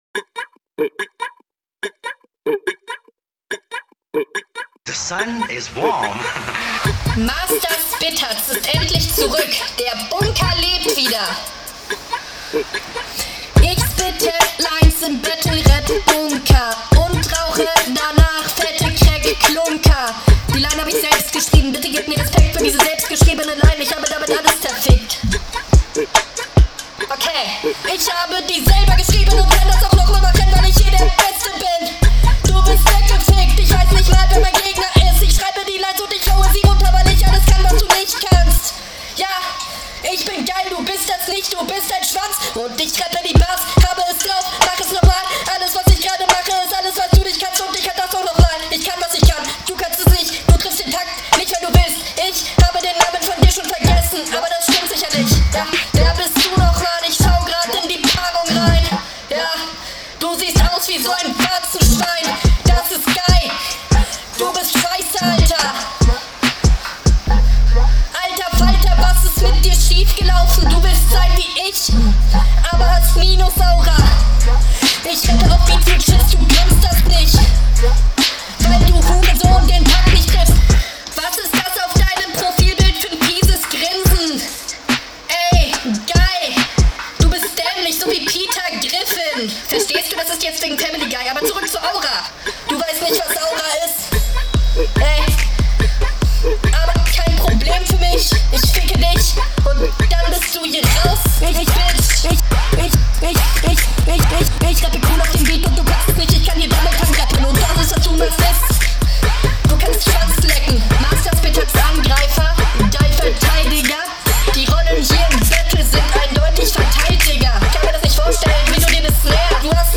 Das ist obviously unhörbar, aber der Flow geht hart.